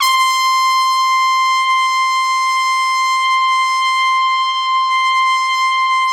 Index of /90_sSampleCDs/Best Service ProSamples vol.20 - Orchestral Brass [AKAI] 1CD/Partition A/VOLUME 002